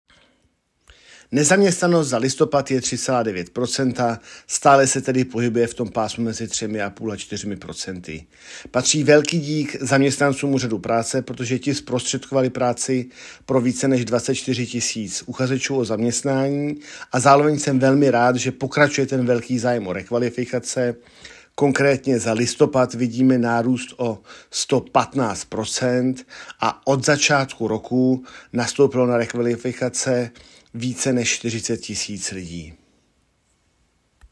KOMENTÁŘ_AUDIO_GŘ_Daniel Krištof_nezaměstnanost